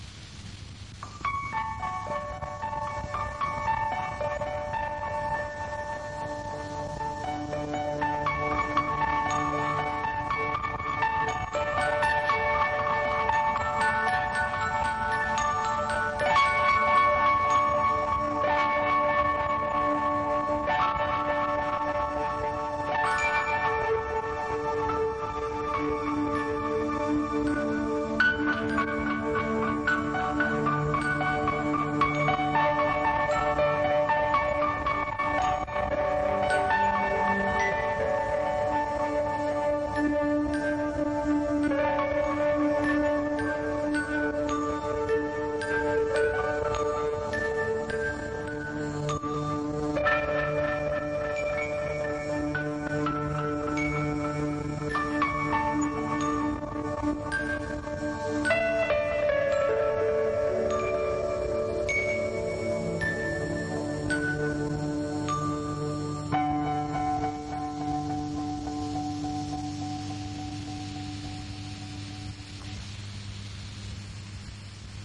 录像机 " VHS卡带插件
描述：将VHS录像带插入飞利浦VR6585录像机。使用Zoom H5和XYH5立体声麦克风录制。
标签： 记录器 磁带 机械的 录像机 播放器 机器 VHS 插入
声道立体声